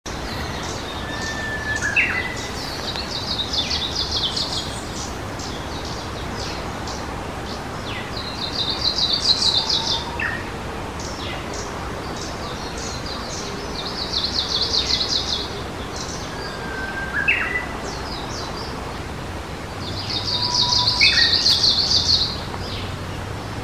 オオムシクイ
今朝もオオムシクイがさえずっていた。
ウグイスとの競演　→